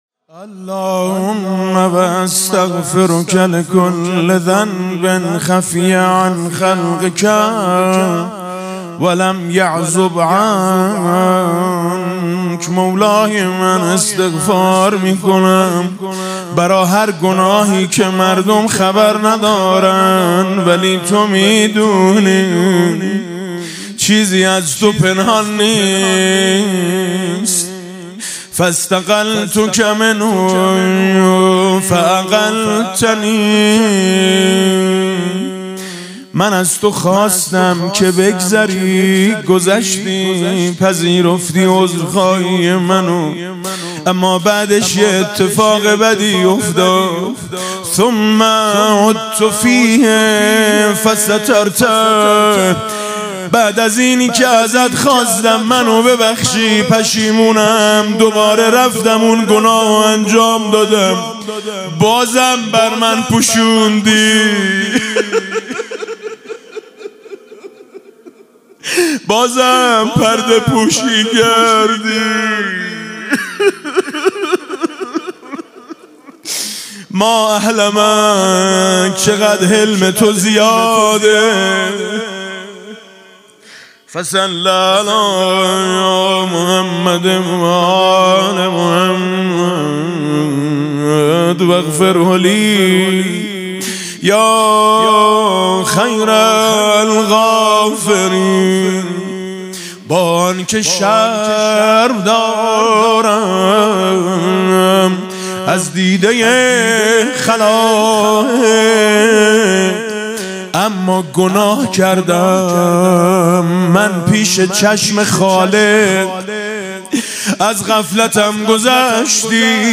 استغفار هفتاد بندی امام علی علیه السلام با نوای میثم مطیعی + متن (بند چهل و یکم) | ضیاءالصالحین
استغفار هفتاد بندی امام علی علیه السلام با نوای میثم مطیعی + متن (بند چهل و یکم) در این بخش از ضیاءالصالحین، چهل و یکمین بخش استغفار هفتاد بندی حضرت امیرالمومنین امام علی علیه السلام را با نوای حاج میثم مطیعی به مدت 2 دقیقه با اهل معرفت و علاقه مندان به دعا و مناجات به اشتراک می گذاریم.